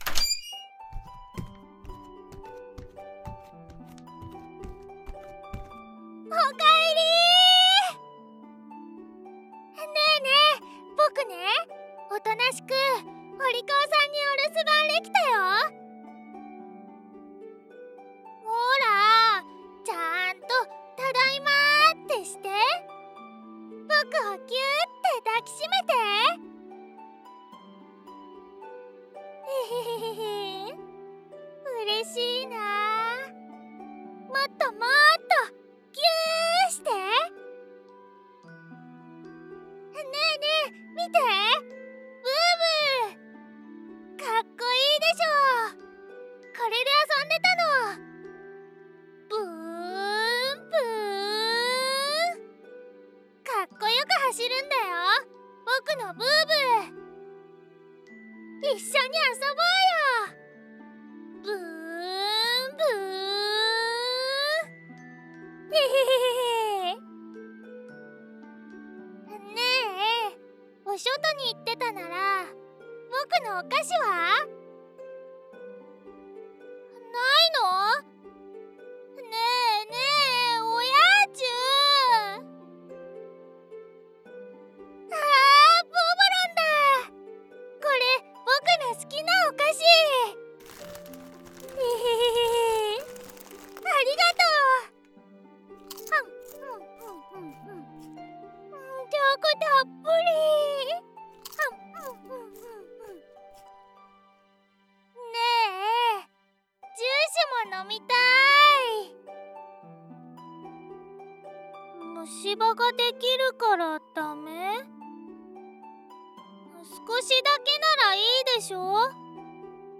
日常/生活 温馨 萌 治愈 少年 正太 弟 系列作品 女性向 乙女向